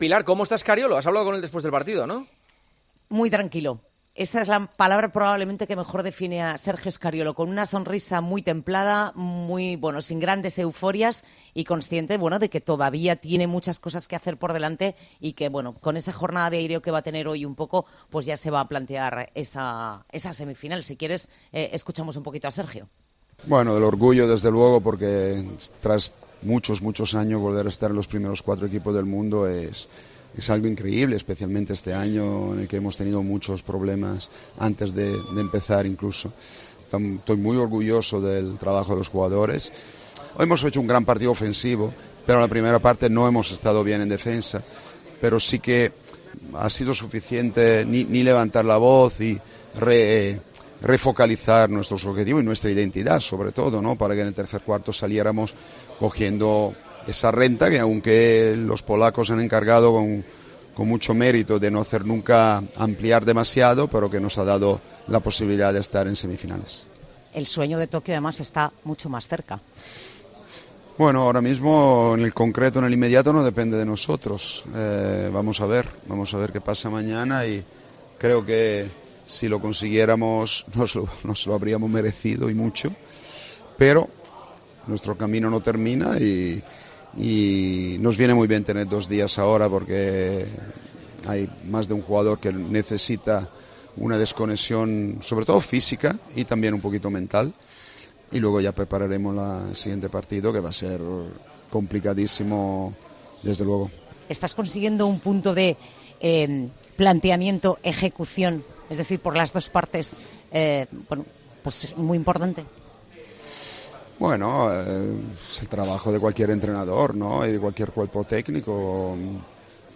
Entrevistas en El Partidazo de COPE
Sergio Scariolo, seleccionador del equipo español de baloncesto, ha pasado por los micrófonos de  El Partidazo de COPE después de la victoria frente a Polonia“Estoy muy orgulloso del trabajo de mis jugadores”, ha comentado.